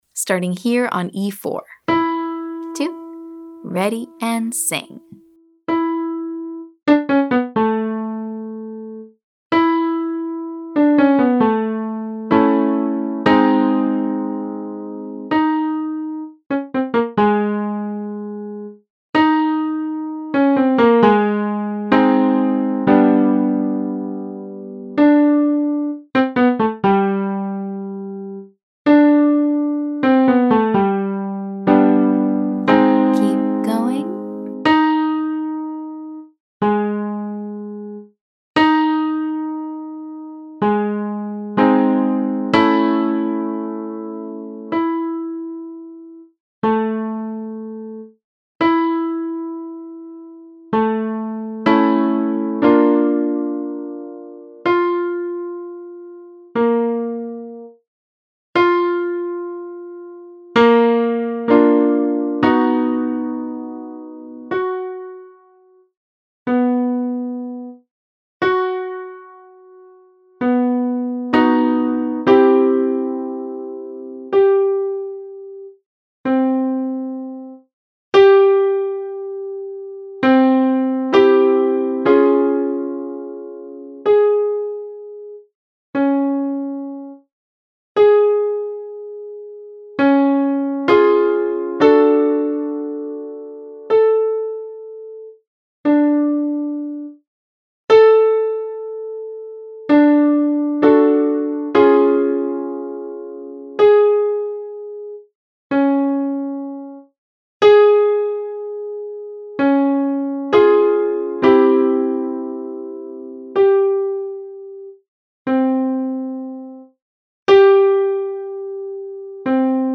Vocal riffs require clarity, agility, and confidence. Start with staccato articulation for distinction, then transition to legato for smoothness.
Exercise 1: RUN 5 4 3 2 (staccato) RUN 54321 (legato)
• Focus on clarity by singing pitches distinctly in staccato before connecting them in legato.